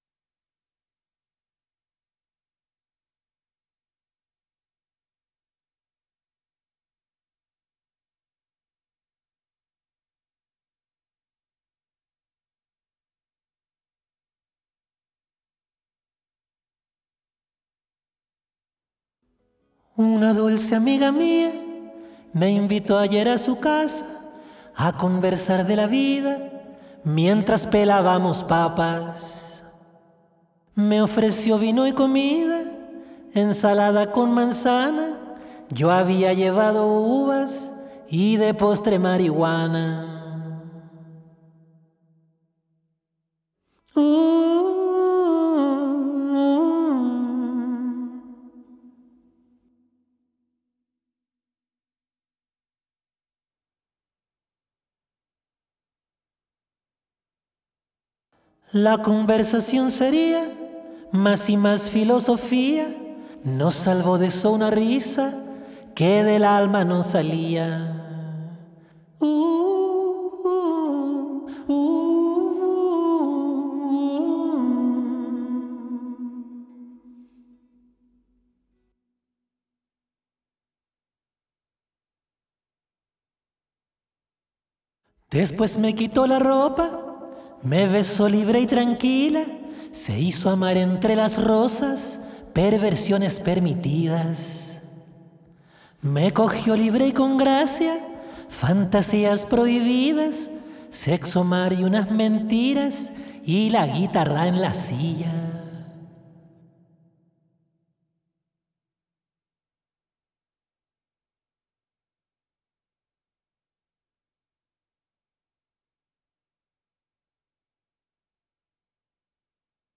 Descargar Voz